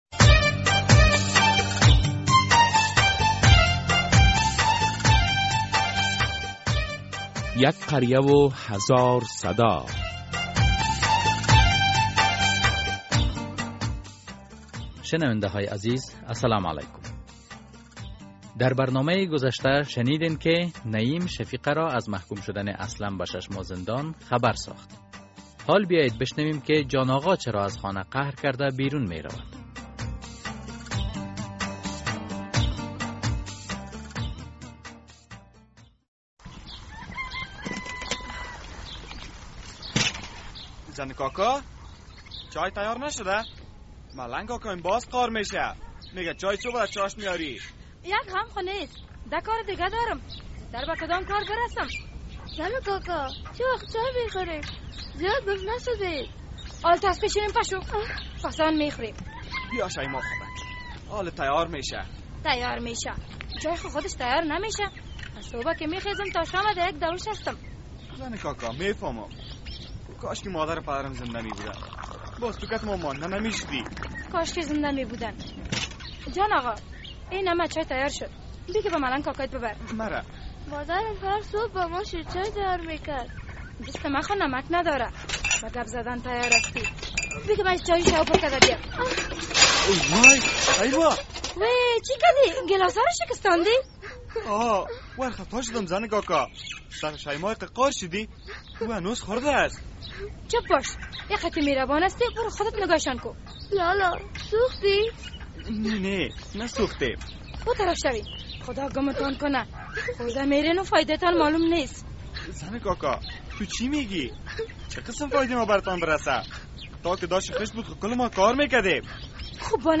در این درامه که موضوعات مختلف مدنی، دینی، اخلاقی، اجتماعی و حقوقی بیان می‌گردد هر هفته به روز های دوشنبه ساعت ۳:۳۰ عصر از رادیو آزادی نشر می‌گردد...